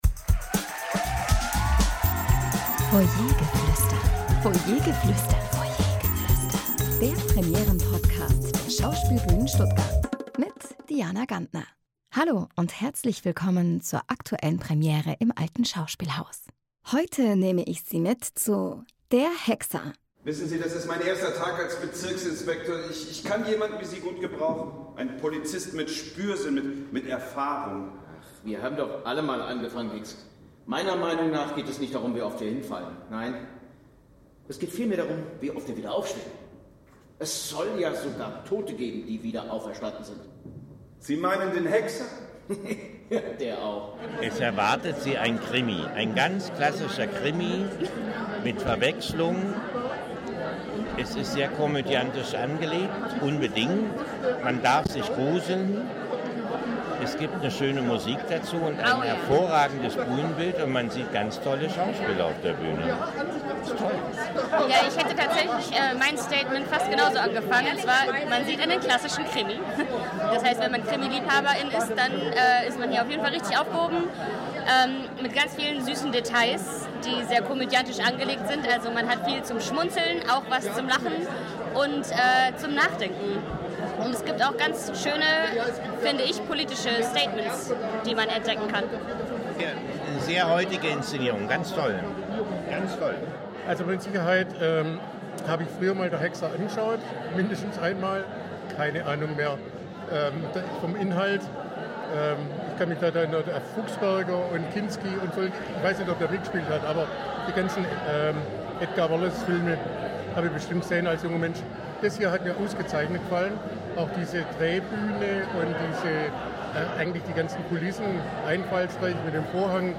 Publikumsstimmen zur Kriminalkomödie “Der Hexer”